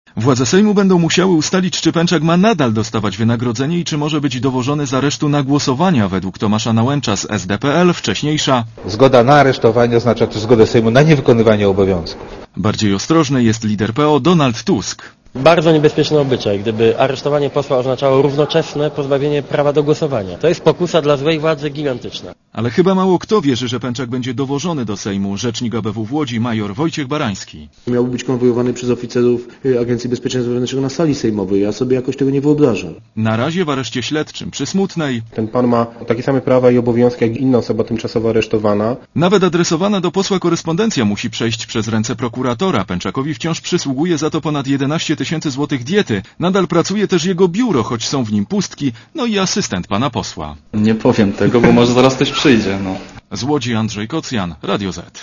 Źródło: PAP Relacja reportera Radia ZET Pęczak jest pierwszym posłem w historii III Rzeczypospolitej, który wykonuje swój mandat zza krat.